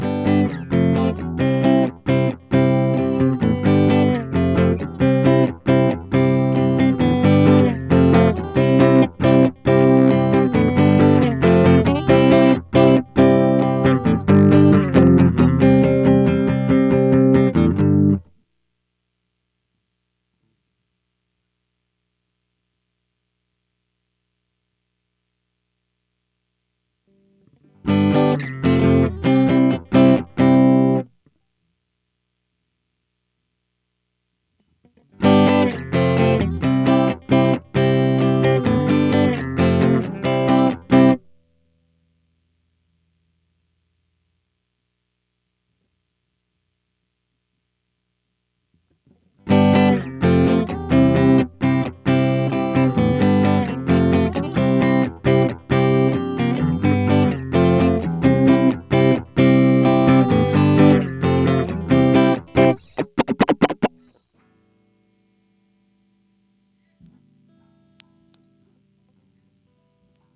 Guitar_009.wav